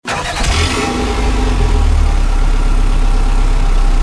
mr2gt_idle.wav